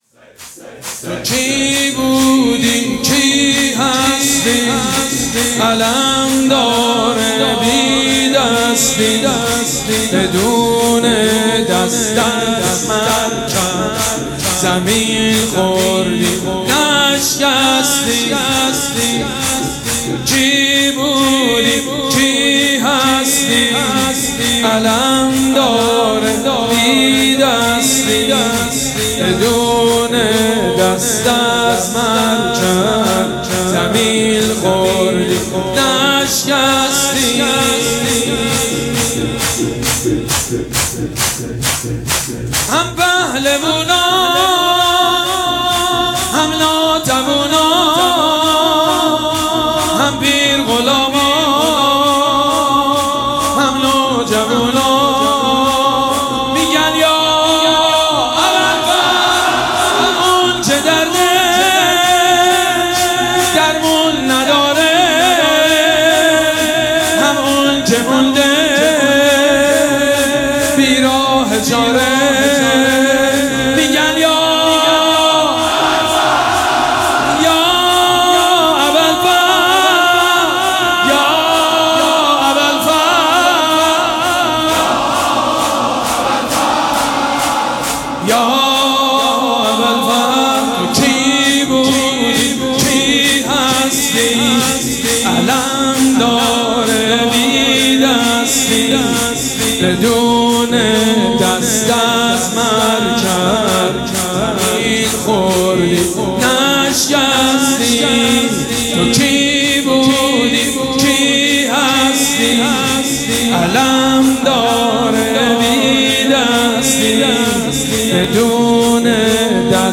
مراسم عزاداری شب دهم محرم الحرام ۱۴۴۷
شور
مداح
حاج سید مجید بنی فاطمه